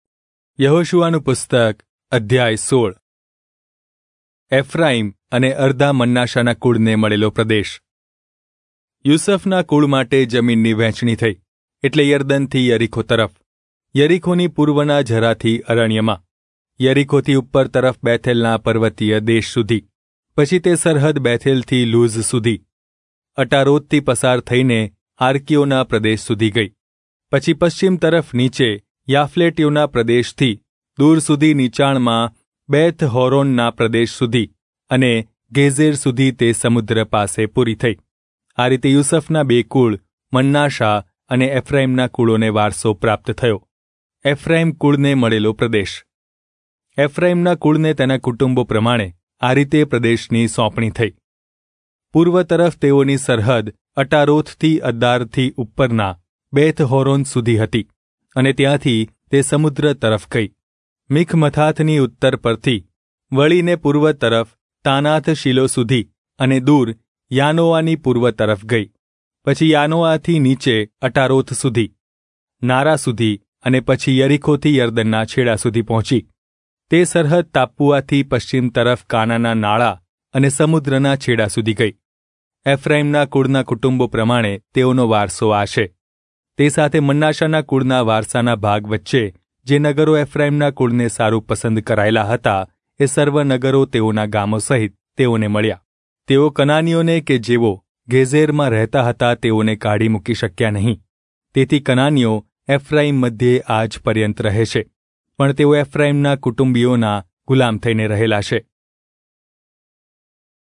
Gujarati Audio Bible - Joshua 13 in Irvgu bible version